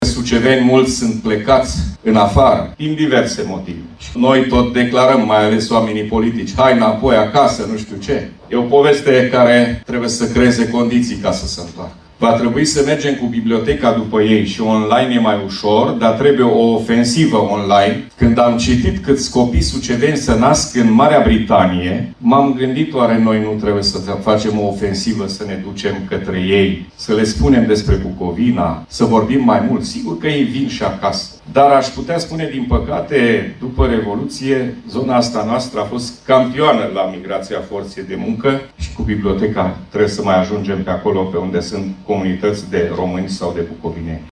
În cadrul ședinței festive la împlinirea unui secol de activitate, FLUTUR a spus că se impune o intensificare a activității în acest domeniu, având în vedere tendințele la nivel mondial.